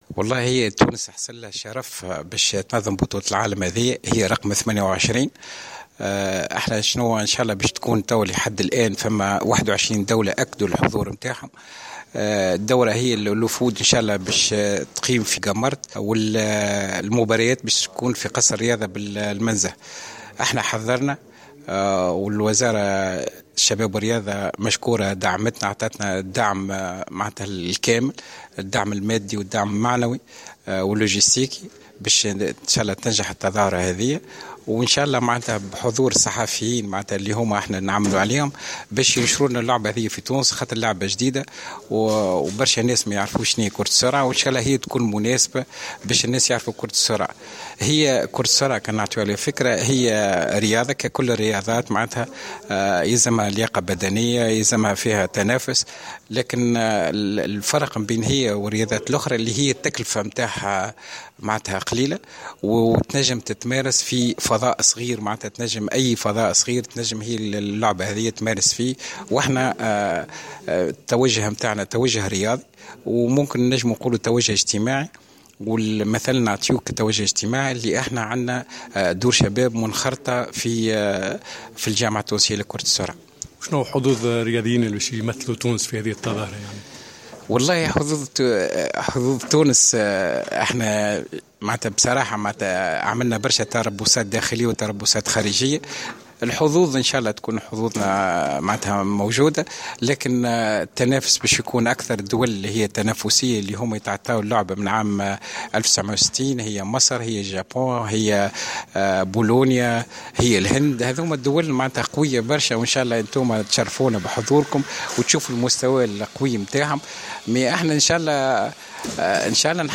الندوة الصحفية لبطولة العالم لكرة السرعة تونس 2016